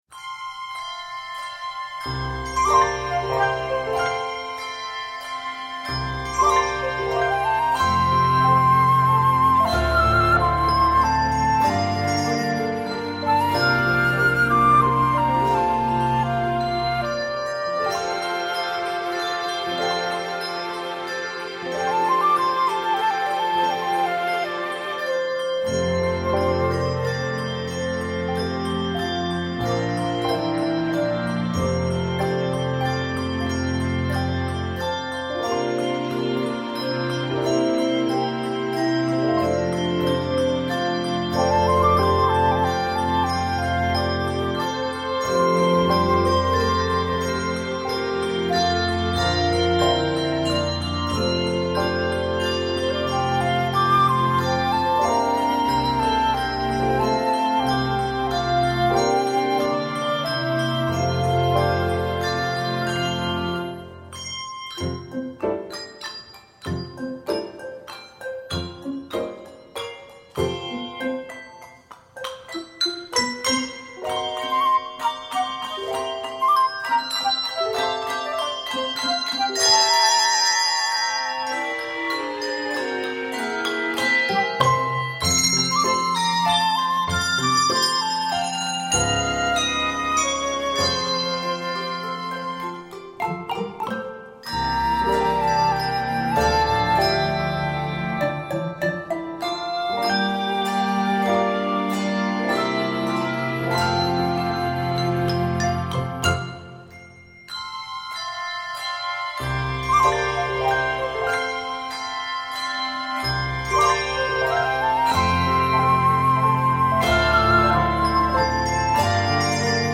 The soothing lullaby moves
it is set in F Major and D Major.